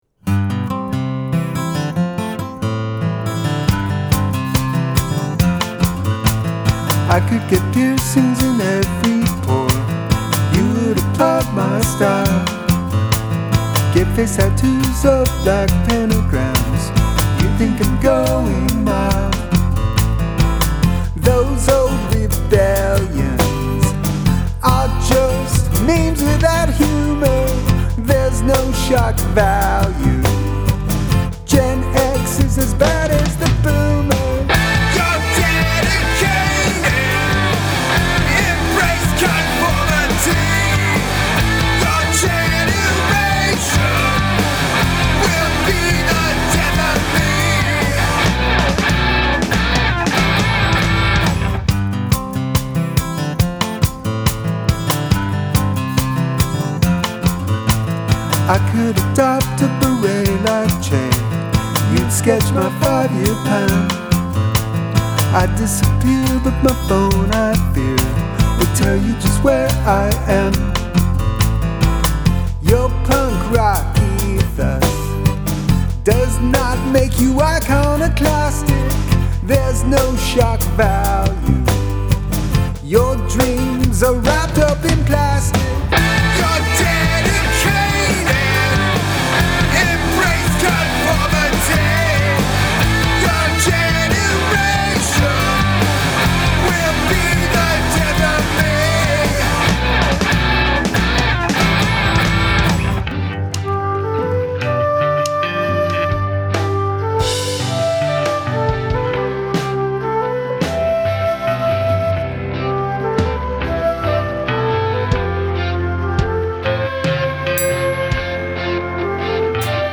The transition to the flute is too abrupt.
Top notch yell-singing.
VERY BOMBASTIC INDEED